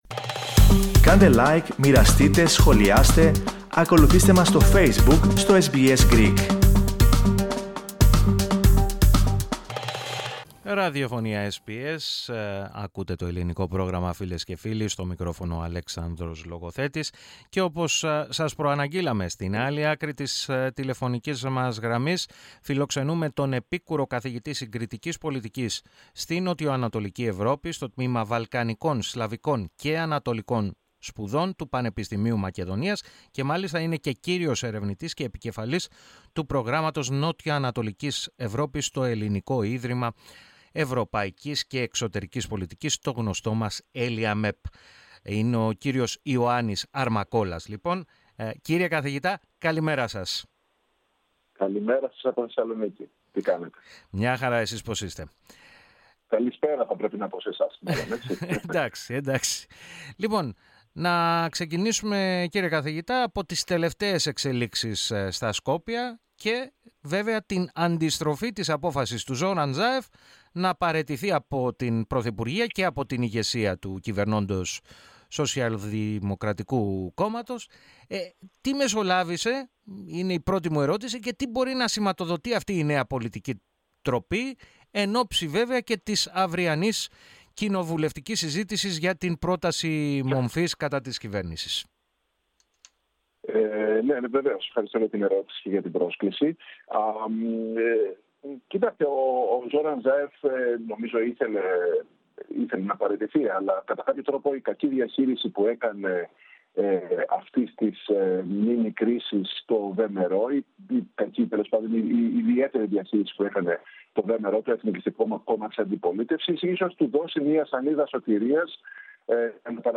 μίλησε στο Ελληνικό Πρόγραμμα της ραδιοφωνίας SBS, με αφορμή τις ραγδαίες πολιτικές εξελίξεις στη Βόρεια Μακεδονία.